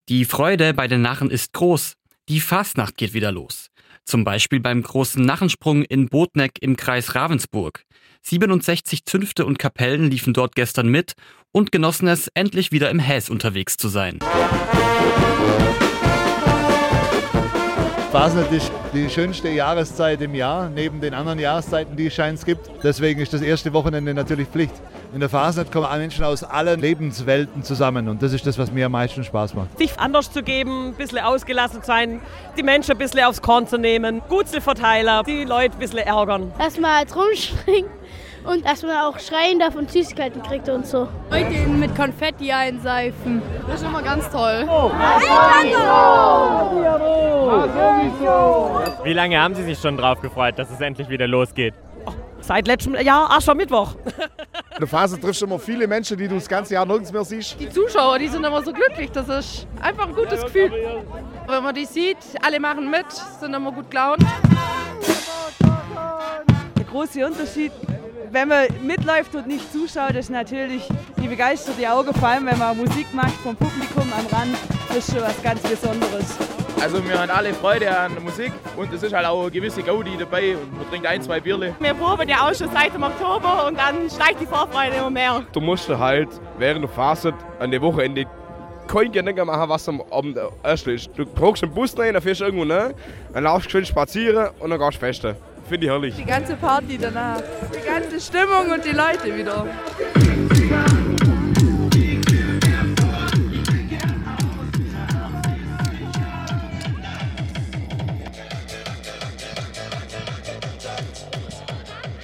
Beim Narrensprung in Bodnegg waren wieder die Narren los.
Hexen, Lumpenkapellen und kreative Kostüme: Viel zu sehen hat es am Sonntag beim Narrensprung in Bodnegg gegeben. 67 Zünfte und Kapellen liefen mit.